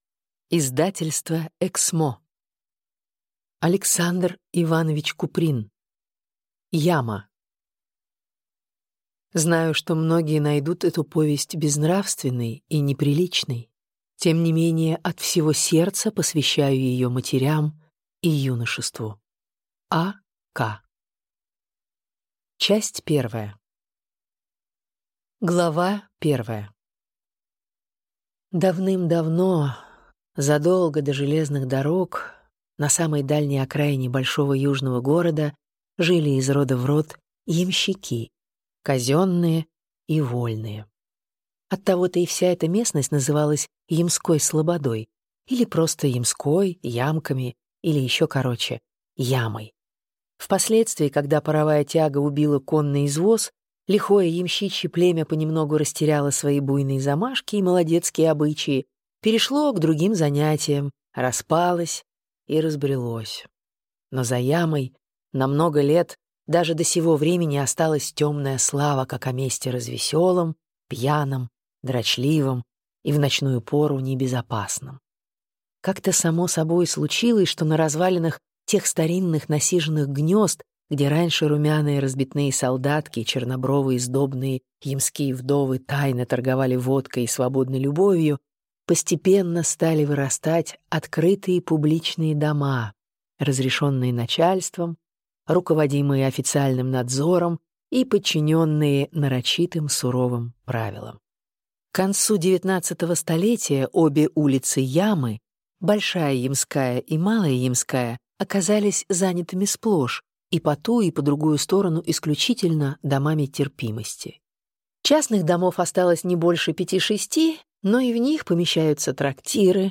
Аудиокнига Яма